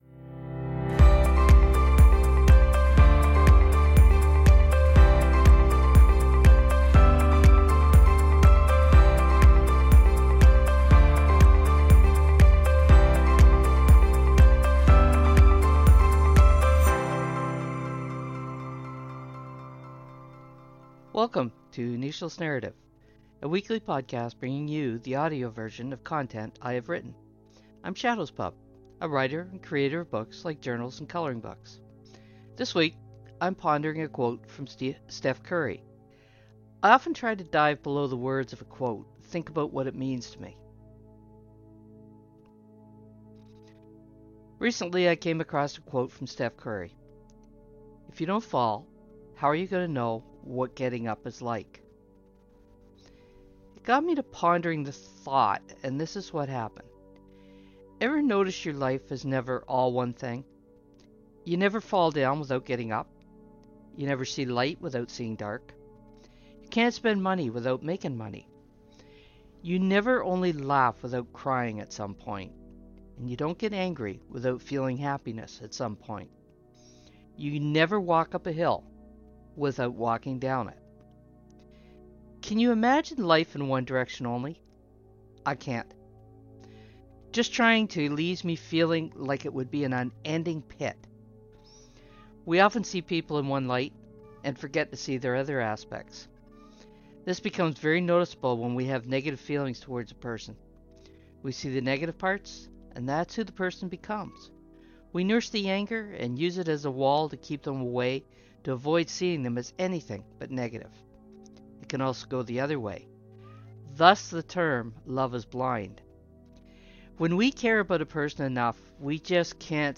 Speaking of ebbs and flows, my cat Tramp decided to add some editorial comment to my recording. She’s kind of mouthy like that.